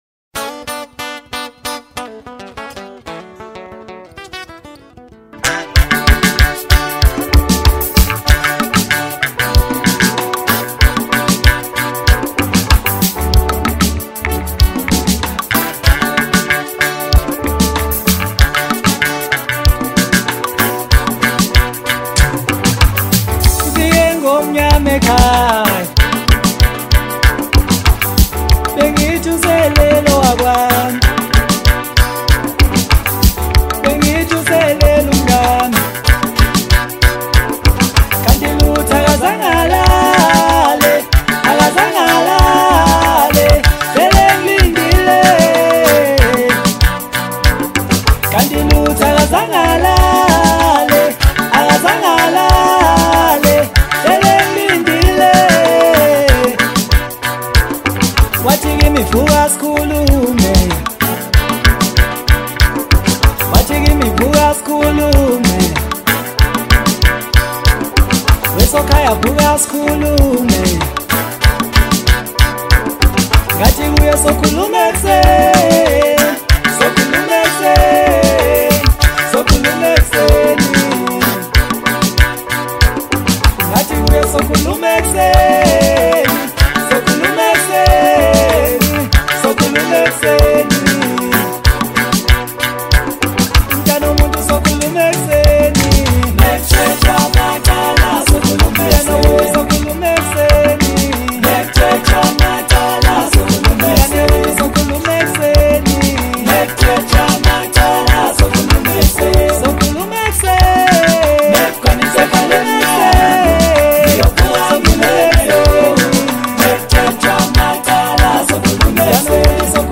Home » Hip Hop » Latest Mix » Maskandi
catchy rhythm, smooth vibe